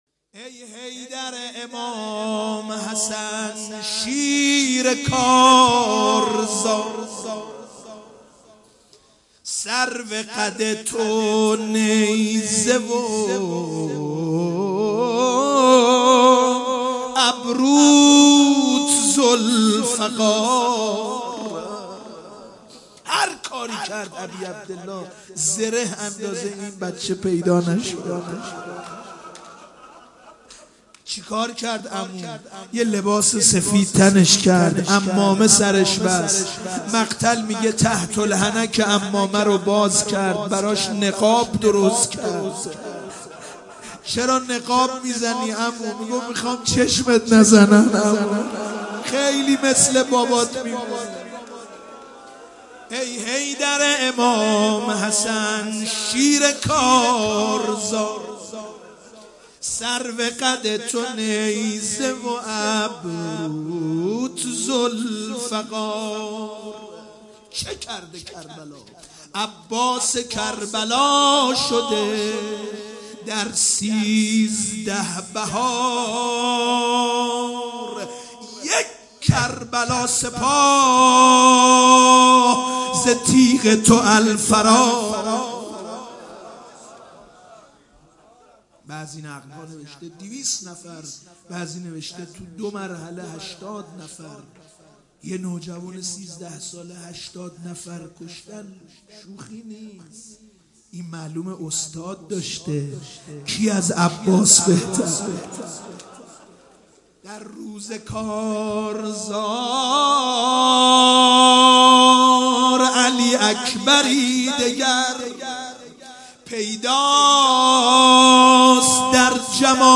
مداحی صوتی